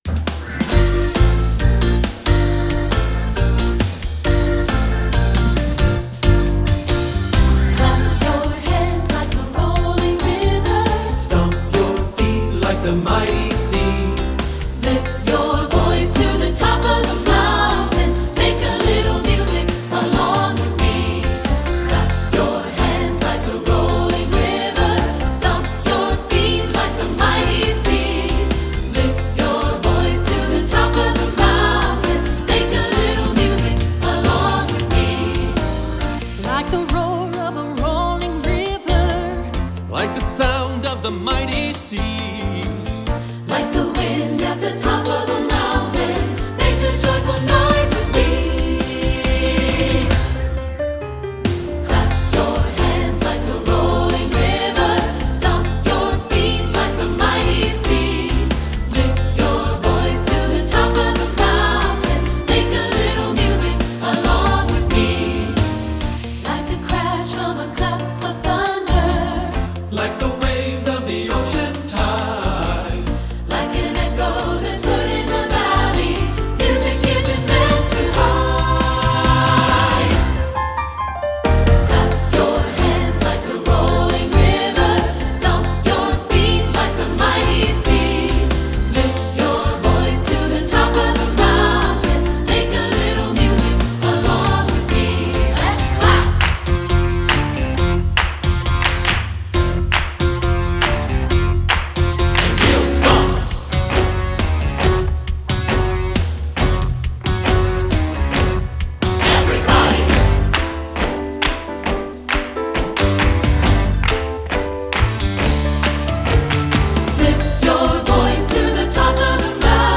Choral Concert/General Spiritual
3 Part Mix